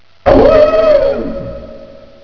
Wolf
WOLF.WAV